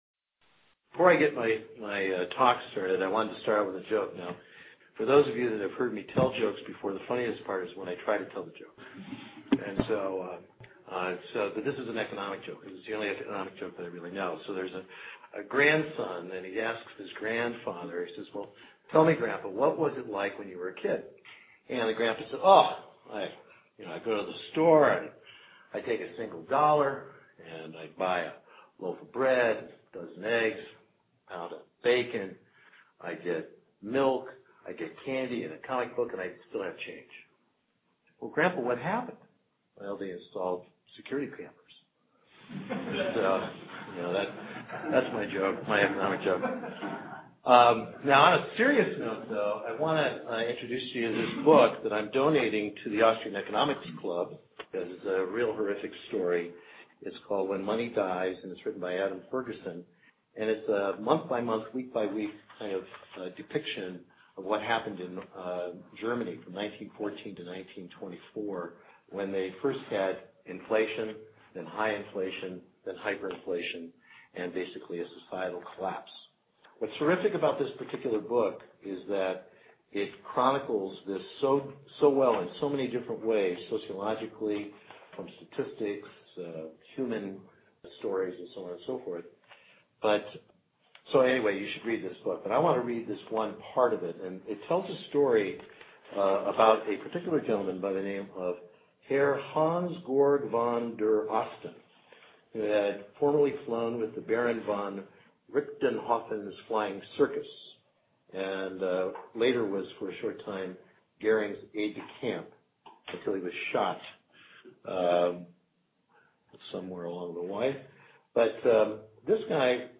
This show is a lecture